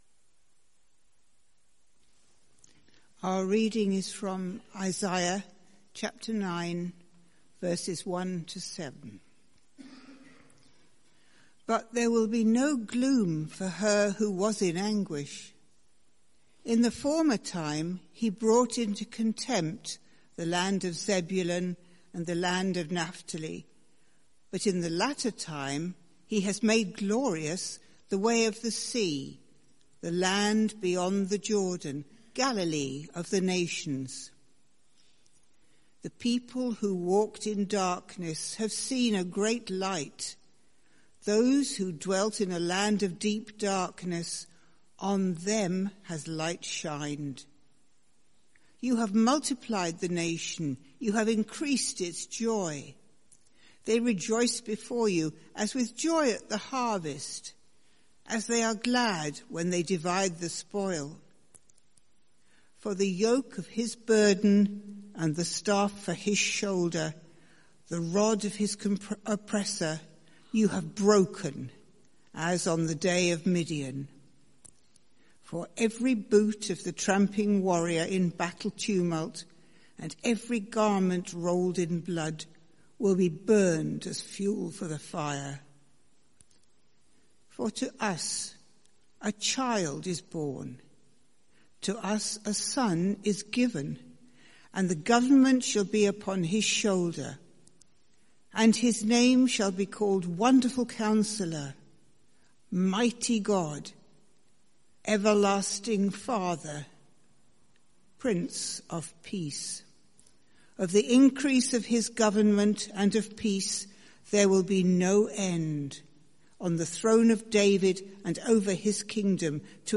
Sermon Series: The Prophecy of Isaiah